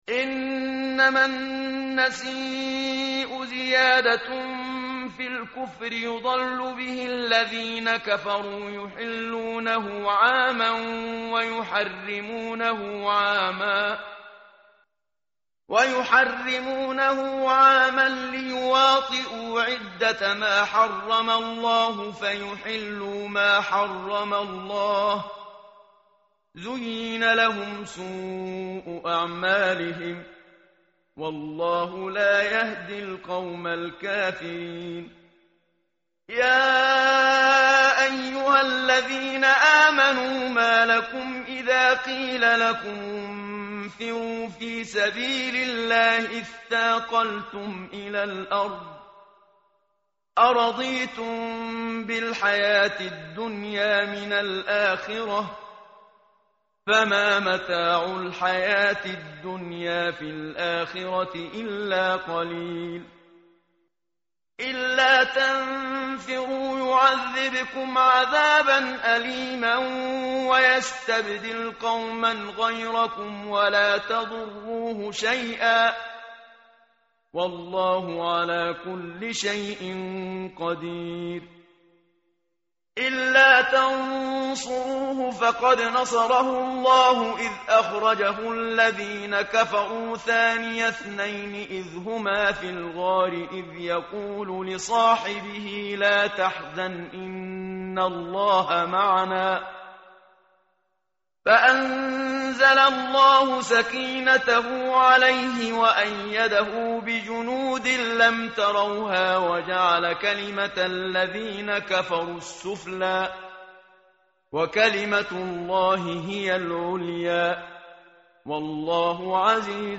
متن قرآن همراه باتلاوت قرآن و ترجمه
tartil_menshavi_page_193.mp3